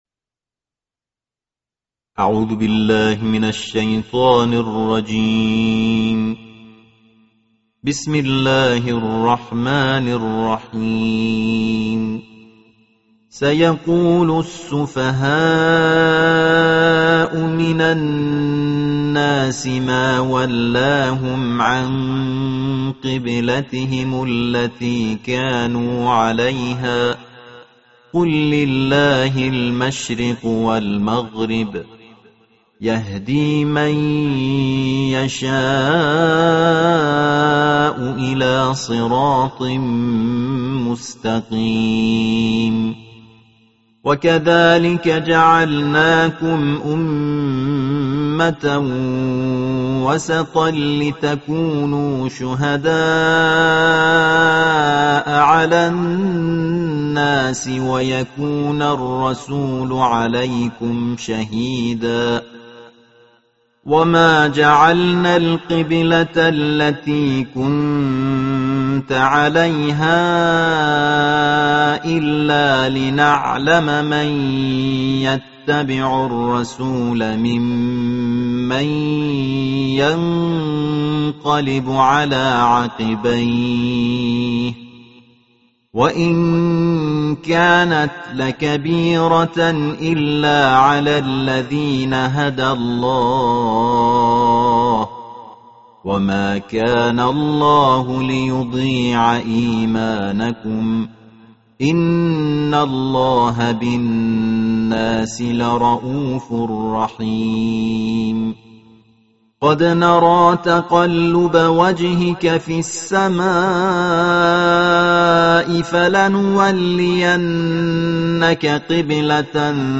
این مدرس قرآن در این تلاوت جدید، سعی داشته تا در کنار اجرای مقامات اصیل عربی، از نغمات فارسی و کشورهای همسایه جهت تلمیح الحان بهره‌مند شود. در دومین روز از ماه مبارک رمضان تلاوت ترتیل جزء دوم قرآن کریم ارائه می‌شود.